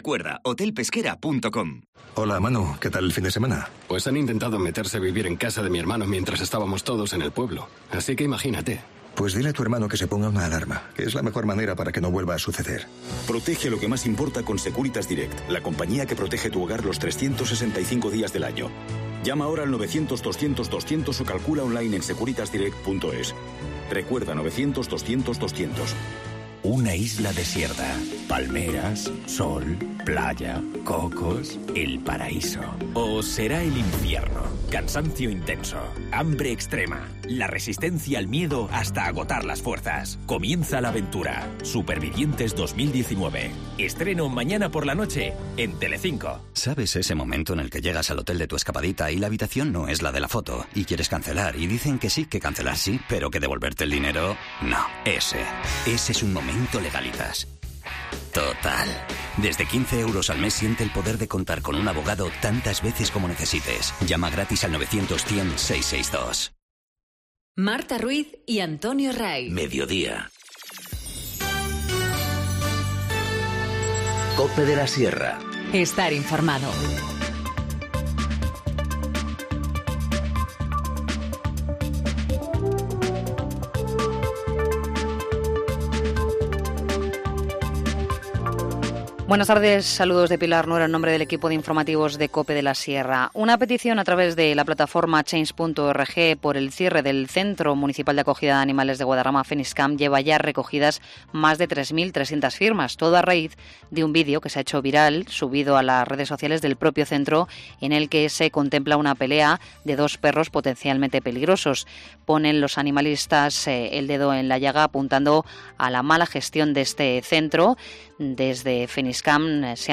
Informativo Mediodía 24 abril 14:20h